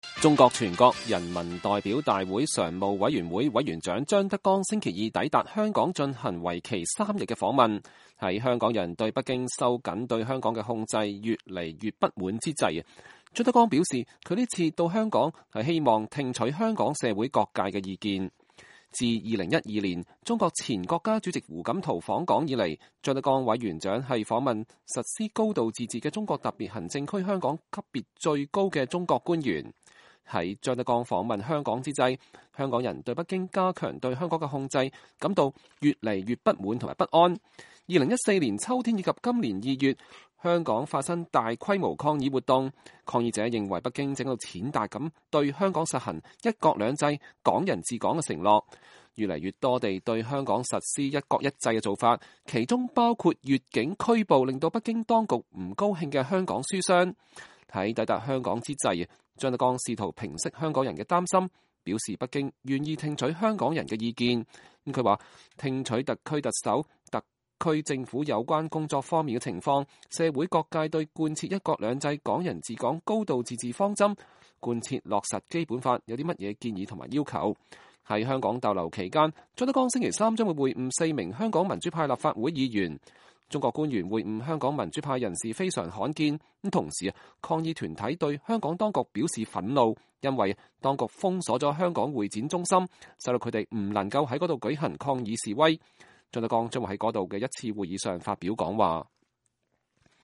張德江星期二抵達香港後在機場對傳媒發表講話